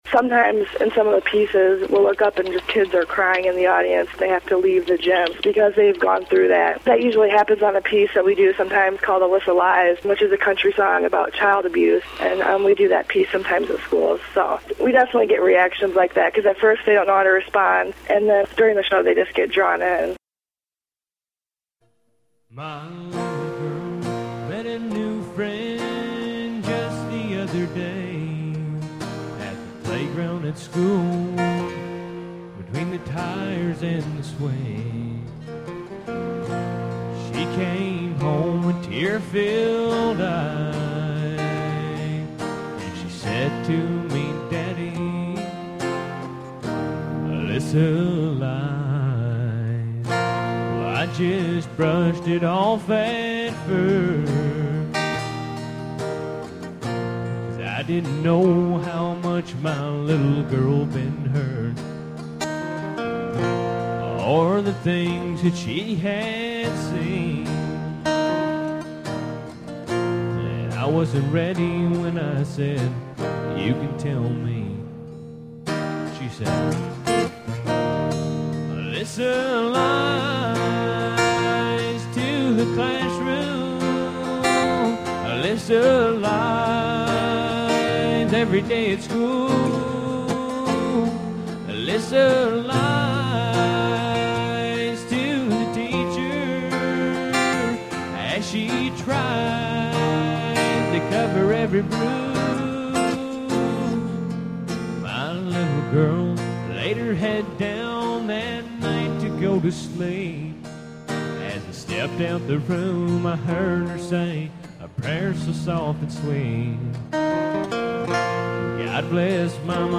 Aired December 04, 2008 on WALL and WGLC Radio Stations of Peru, Illinois